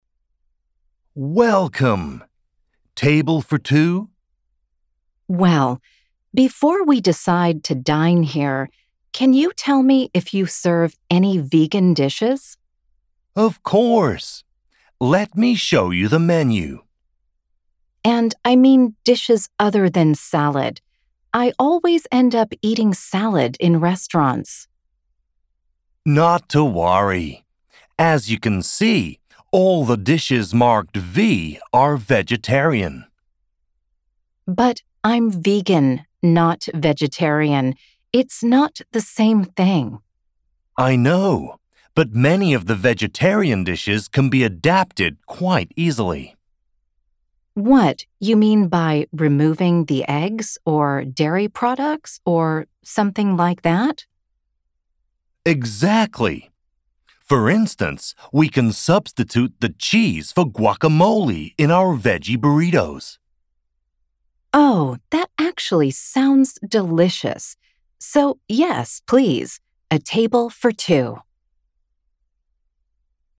Speaker (UK accent)